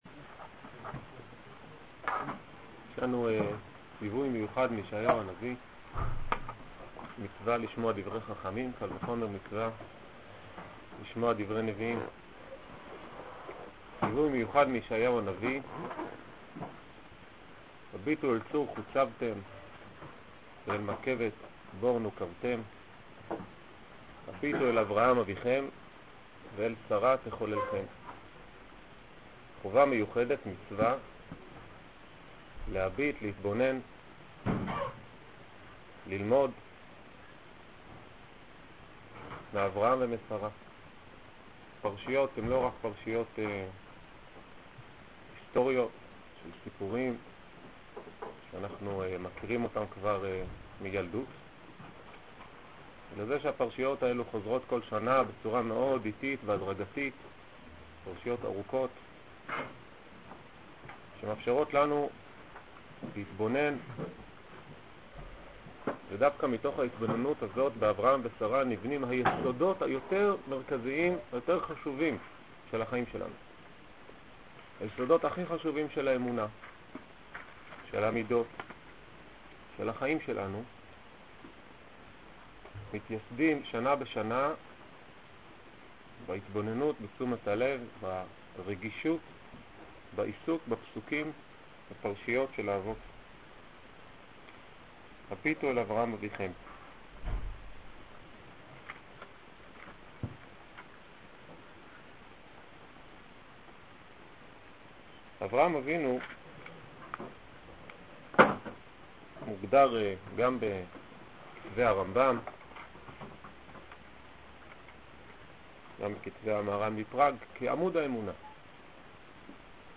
שיעור כללי – החסד כהשקפה על המציאות